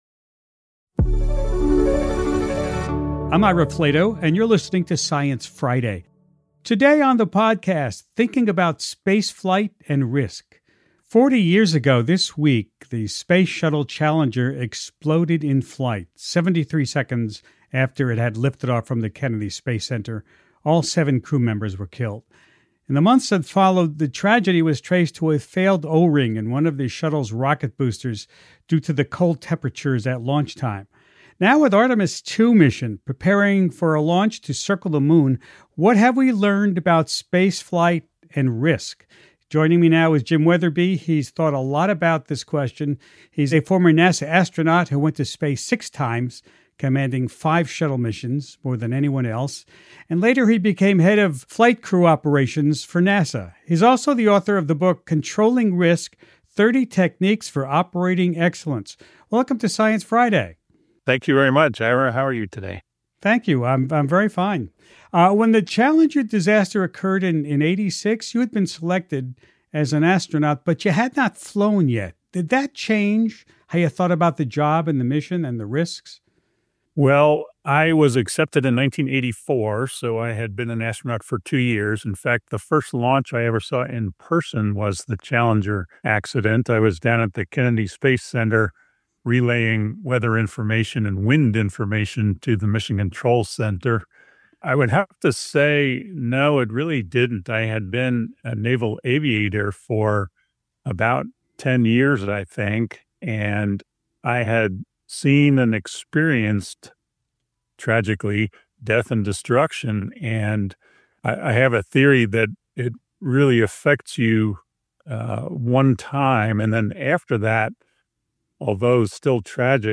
Former astronaut Jim Wetherbee joins Host Ira Flatow to remember the Challenger tragedy, and look ahead to the age of private spaceflight and the upcoming Artemis II mission.